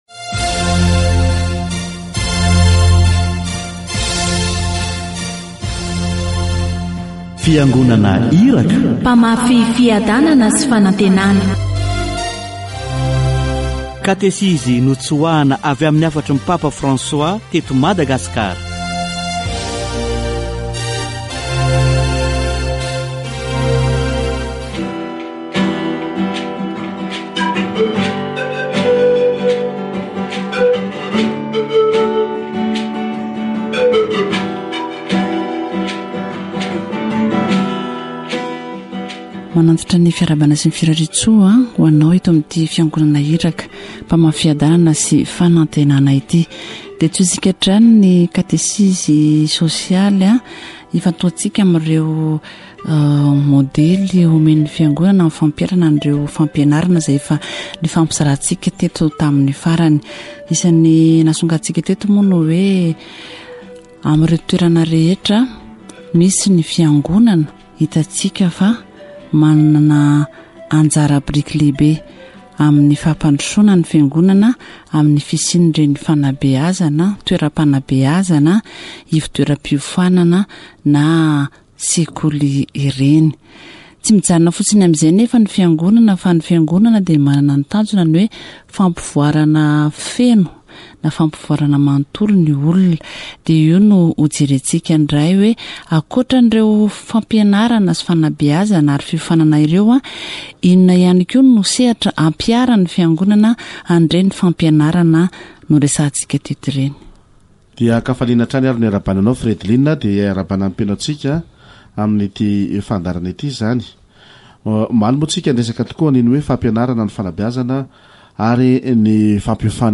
The training offered by the church covers many areas such as crafts, agriculture and breeding. Catechesis on social ministry